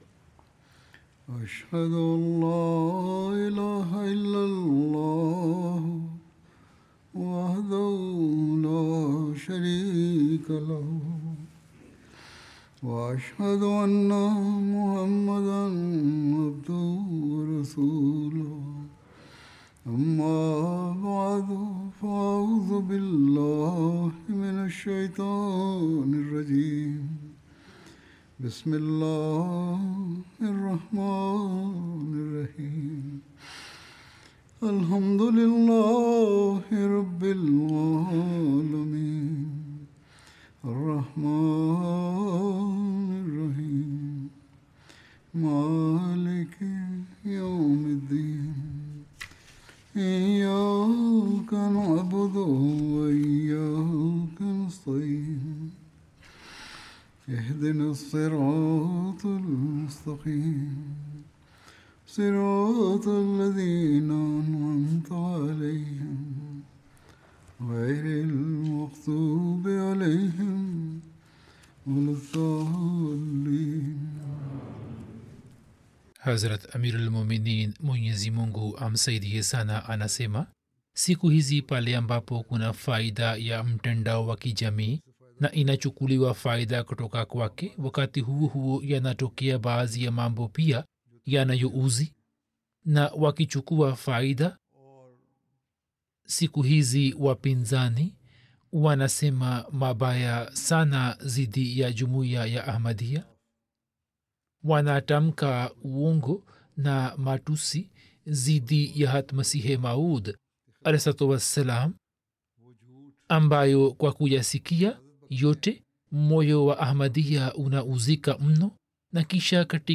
Swahili Friday Sermon by Head of Ahmadiyya Muslim Community
Swahili Translation of Friday Sermon delivered by Khalifatul Masih